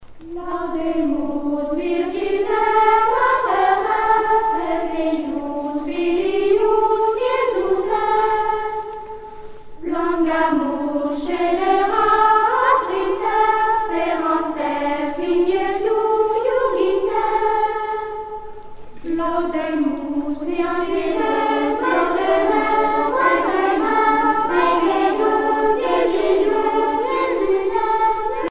canon circulaire appelé caça